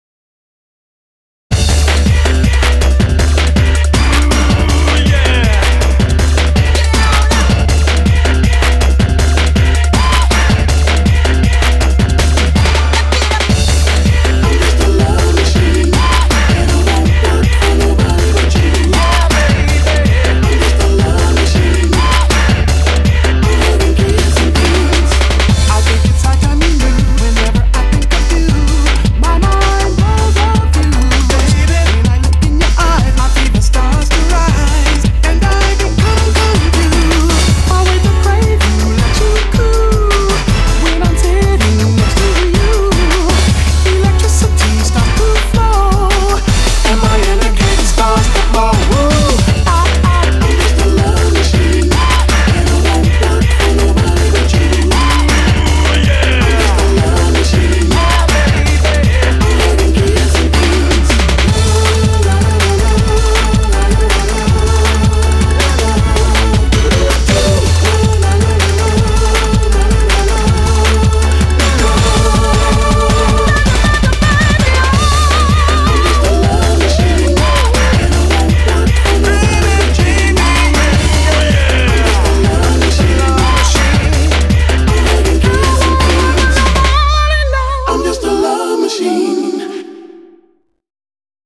BPM 160
Audio Quality Perfect (High Quality)